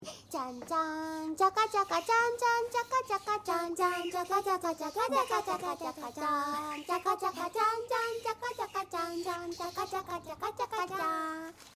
applausee.mp3